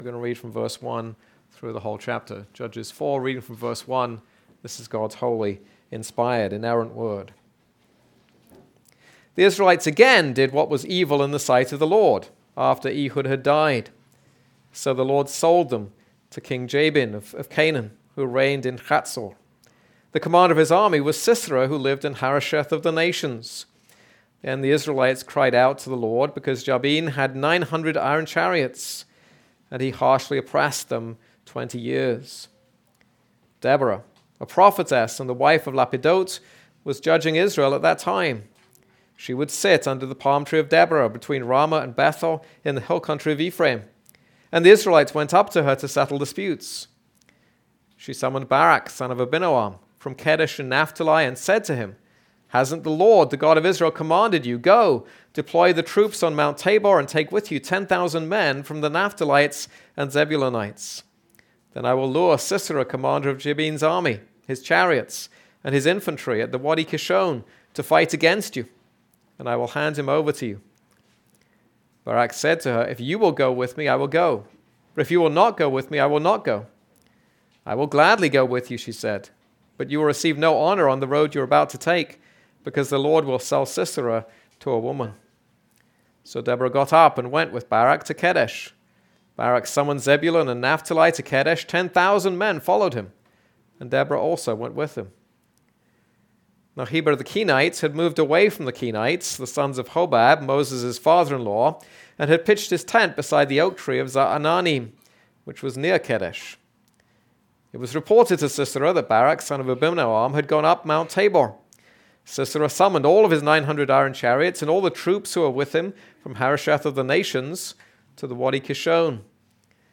This is a sermon on Judges 4.